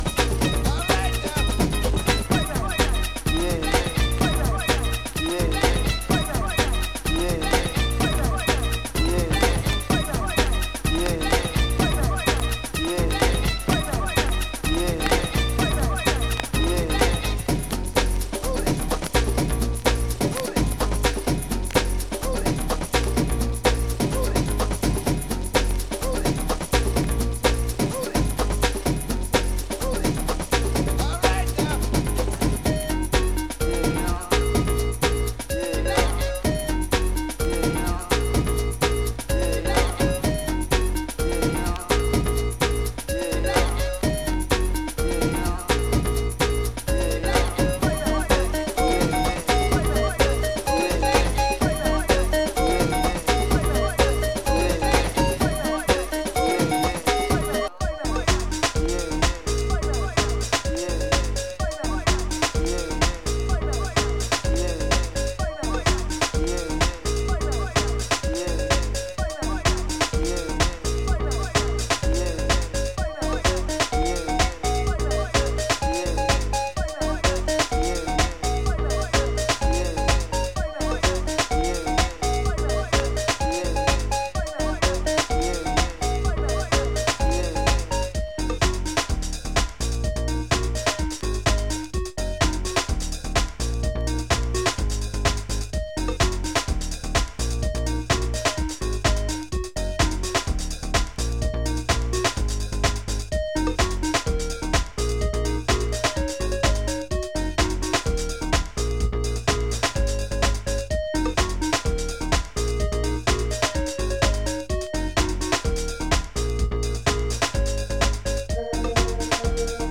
Surface marks and scratches. Vinyl plays to a VG+ grading